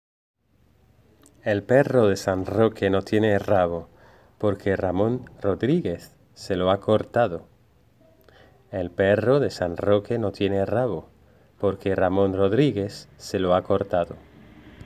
RamonRamón (trabalenguas leído por el profesor)